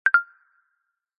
Звуковой сигнал исходящего сообщения в игре